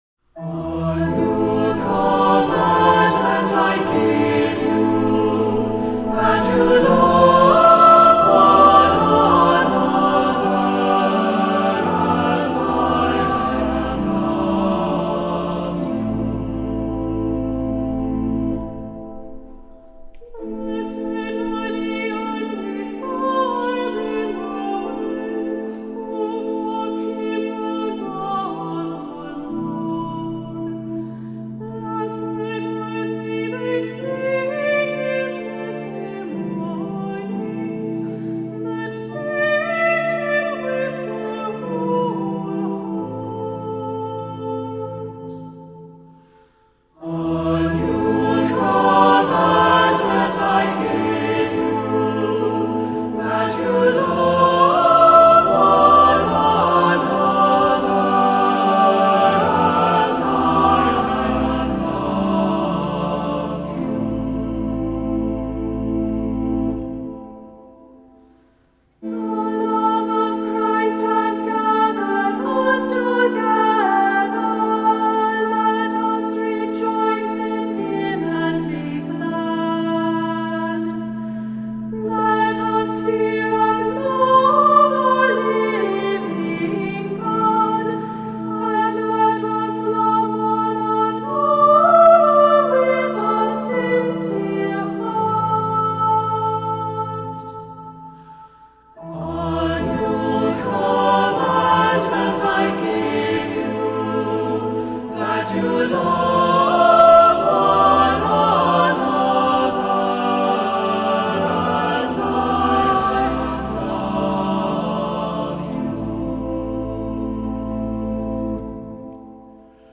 Voicing: SATB; Cantor